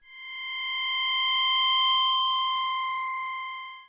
ihob/Assets/Extensions/RetroGamesSoundFX/Machine/Machine24.wav at master